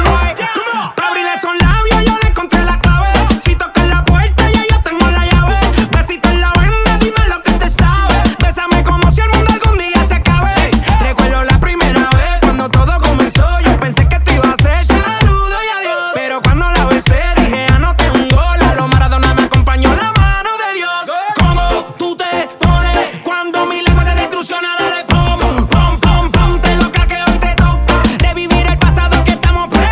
Genre: Pop Latino